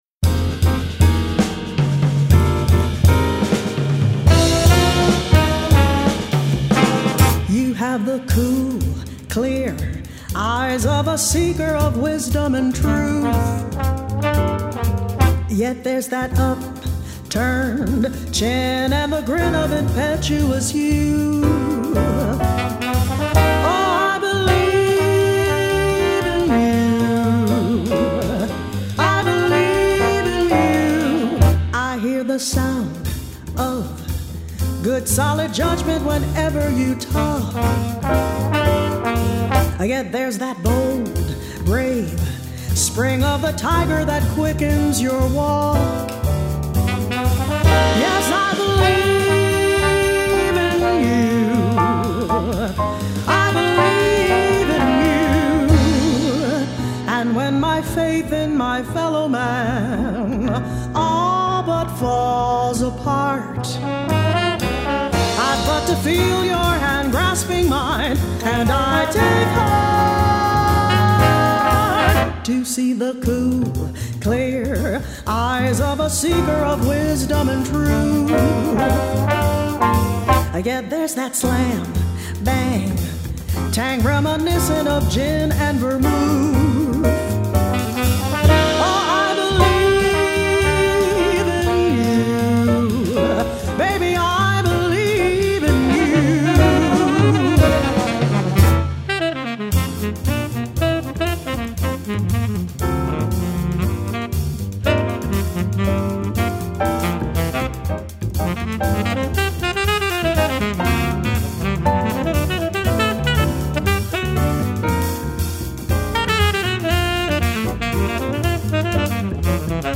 en concert
guitare
saxophone
orgue Hammond
batterie